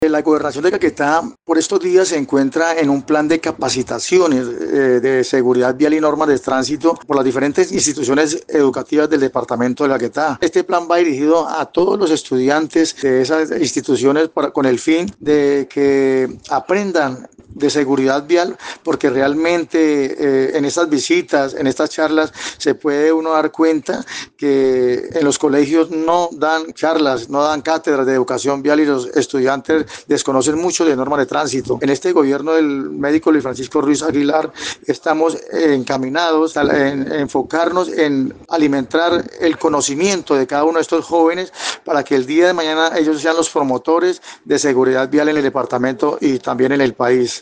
Hernán Castañeda Suarez, director de tránsito departamental, explicó que de esta manera se suple la necesidad existente de capacitaciones en temas viales, especialmente en lo referente al respeto por las normas y al acatamiento de las mismas en carretera.